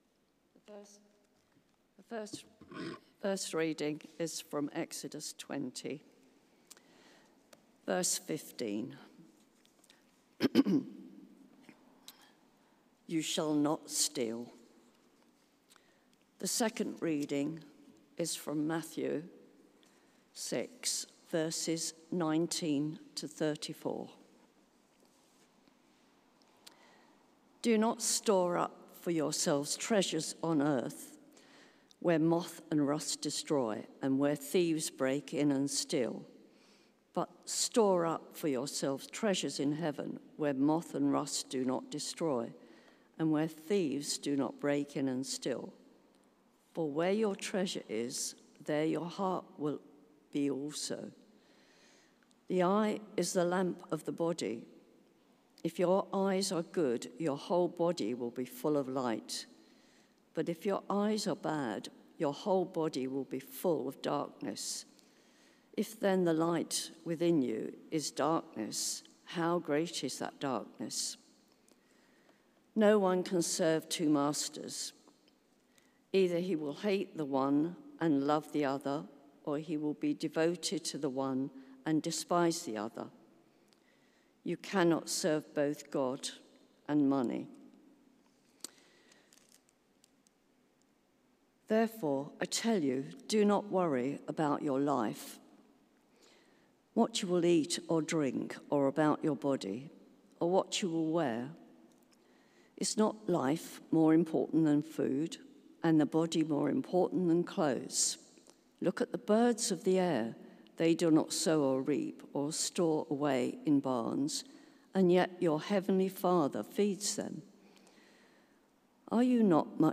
Series: The Ten Commandments Theme: Hands Off! Do Not Steal Sermon Search: